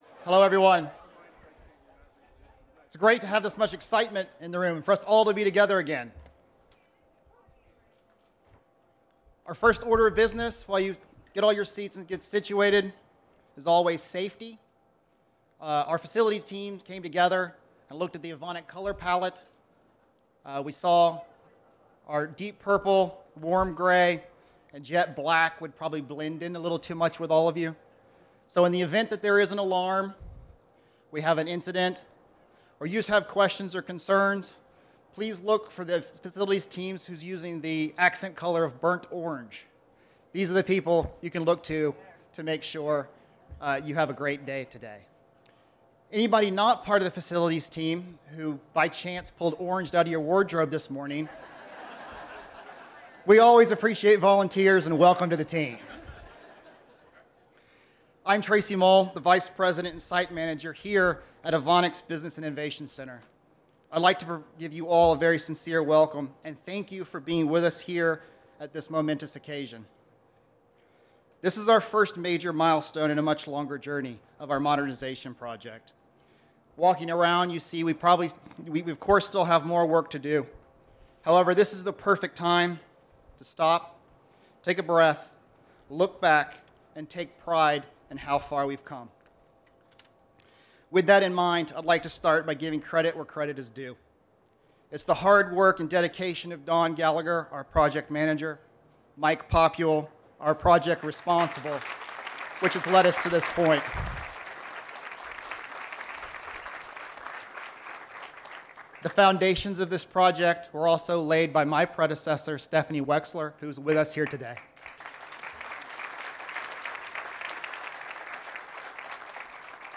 Governor Tom Wolf today joined Evonik, an international leader in specialty chemicals manufacturing, for a ribbon cutting celebrating the opening of their new Allentown Innovation Hub.